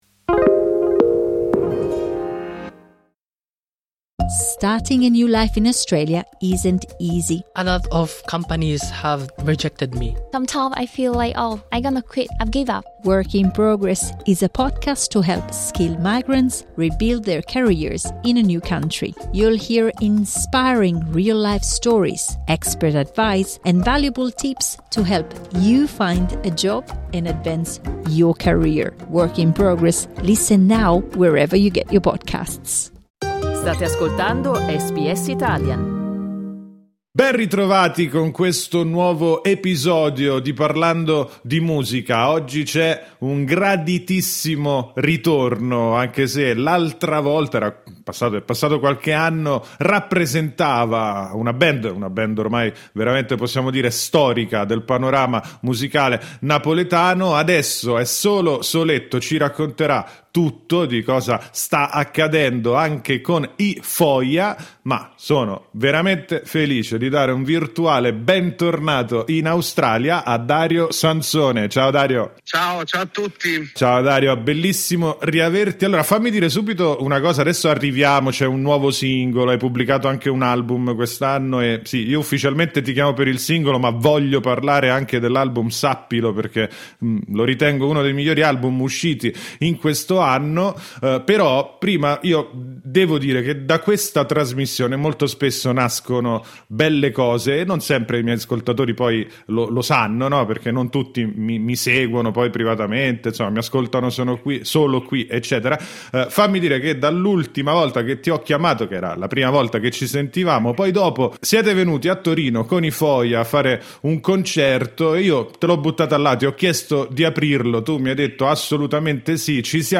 In questo incontro ci parla del suo nuovo singolo, "Restiamo in piedi".